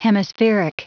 Prononciation du mot hemispheric en anglais (fichier audio)